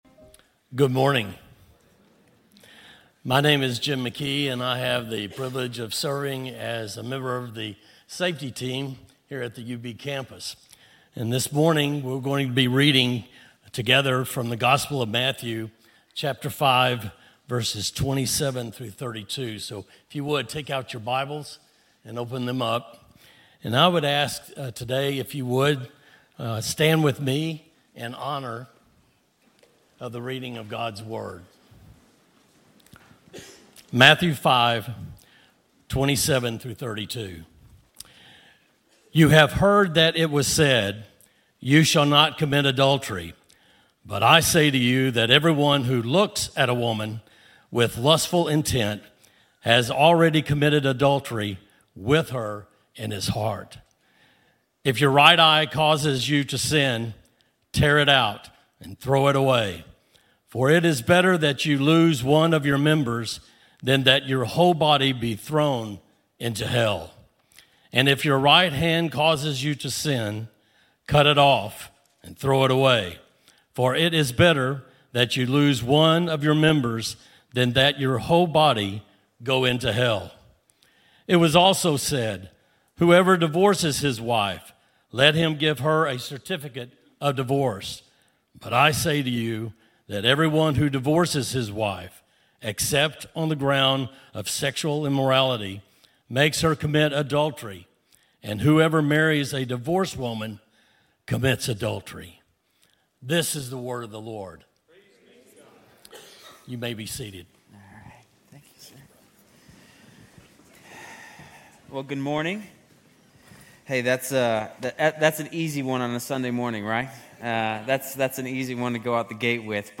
Grace Community Church University Blvd Campus Sermons 2_23 University Blvd Campus Feb 23 2025 | 00:36:22 1x 00:00 / 00:36:22 Subscribe Share RSS Feed Share Link Embed